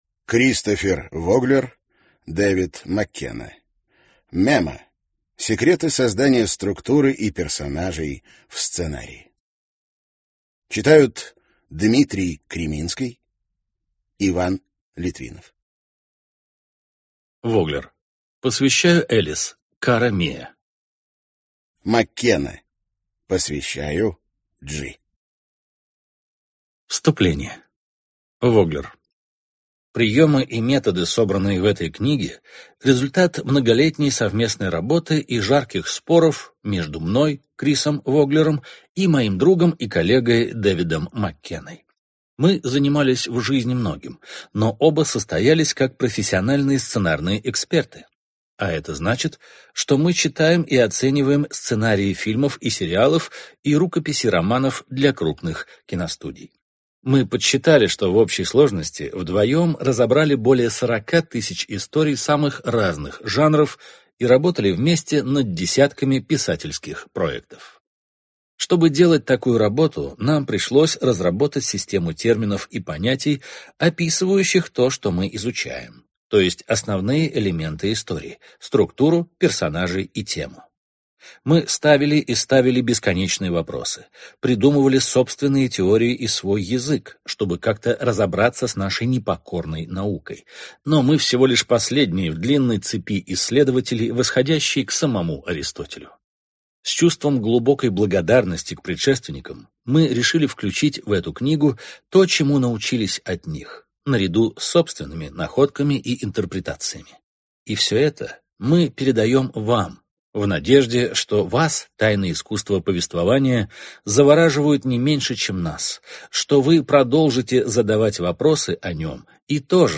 Аудиокнига Memo: Секреты создания структуры и персонажей в сценарии | Библиотека аудиокниг